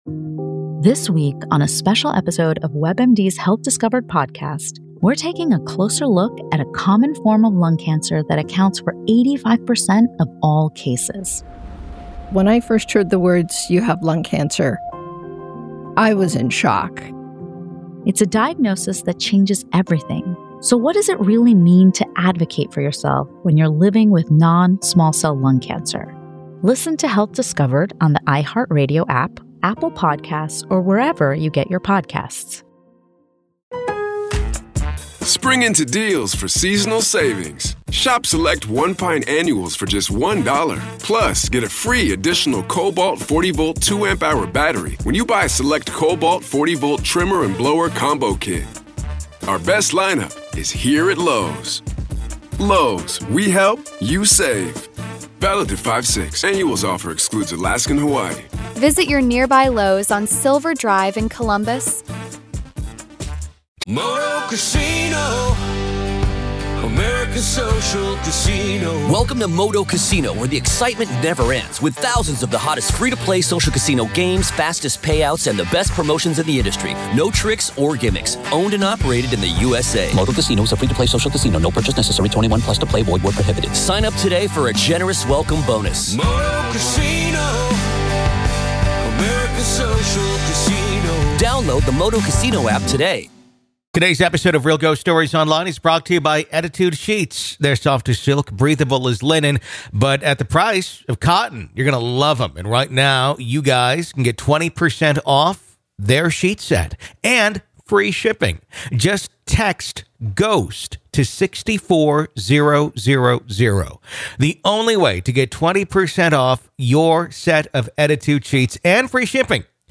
Today we take calls from the living who have experienced real interactions with the dead. True ghost stories told by real people!